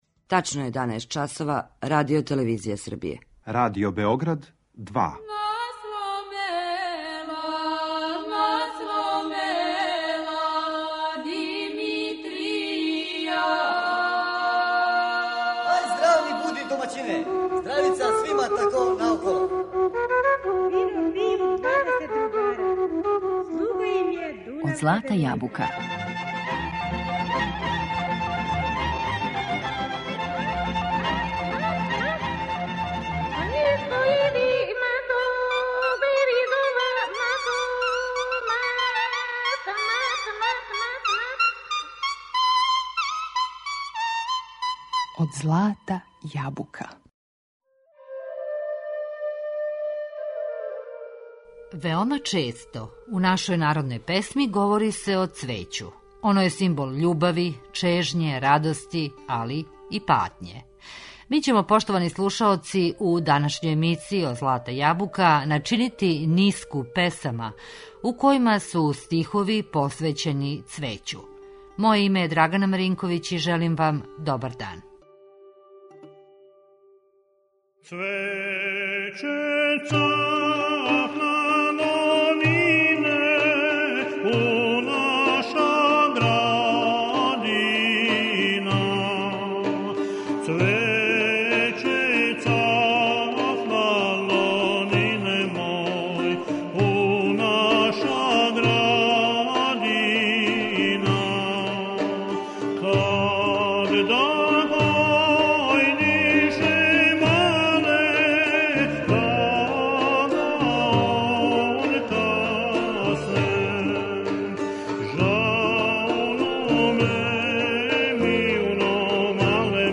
Изворно музичко стваралаштво